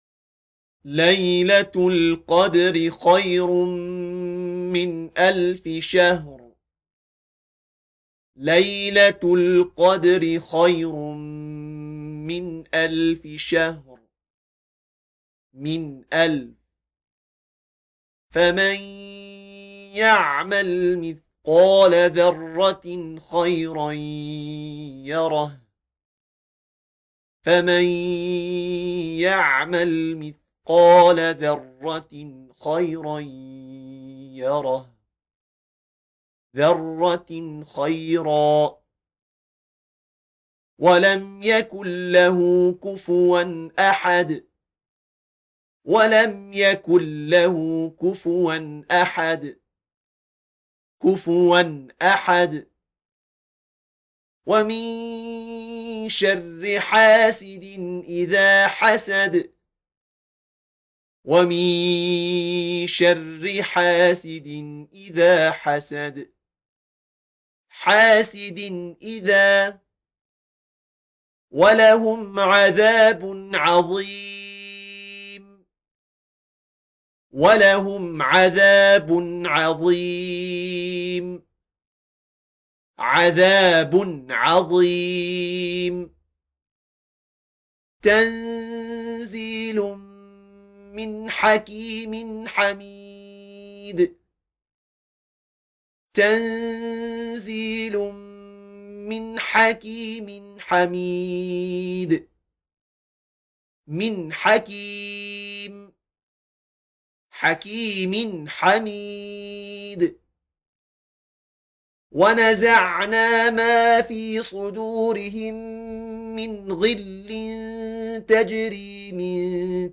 Although an audio recording is included to demonstrate the correct pronunciation of these rules, it is highly recommended to use this book in conjunction with a teacher versed in the rules of Qur’anic recitation.